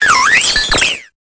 Cri de Grelaçon dans Pokémon Épée et Bouclier.